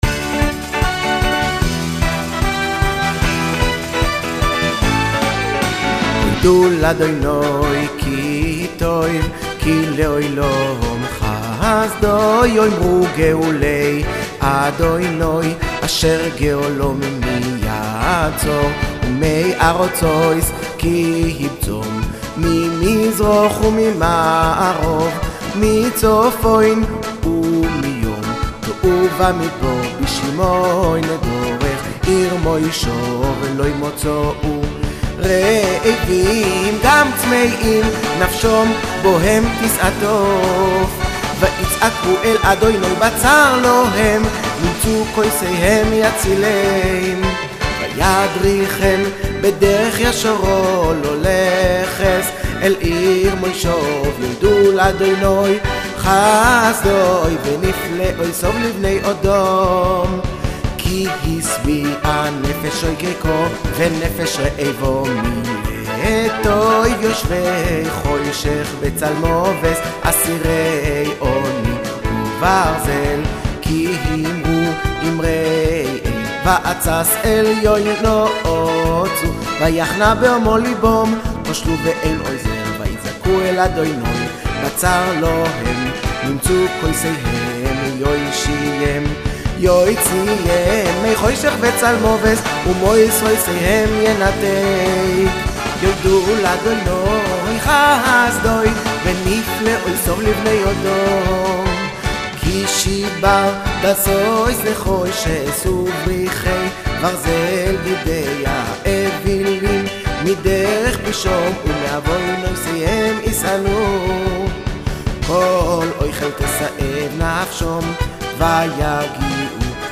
בלחן חבד"י
במנגינת מארש נפולאון.